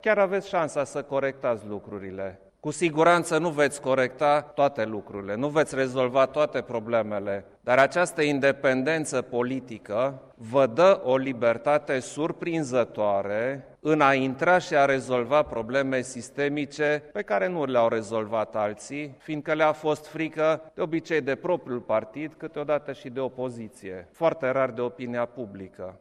Expresia „pacientul în centrul sistemului” trebuie să nu fie un slogan, ci o realitate, a subliniat şi preşedintele Klaus Iohannis în cadrul ceremoniei de la Palatul Cotroceni în care noul ministru al sănătăţii a depus jurământul de învestitură.